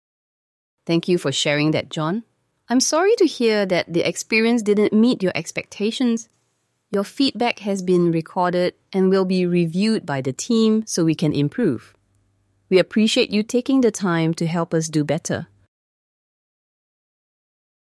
These are sample AI-driven voice interactions, adapted dynamically based on how customers respond
Malaysia | English | AI detects negative sentiment, responds with a natural apology
sample-audio-ai-empathetic-closing.mp3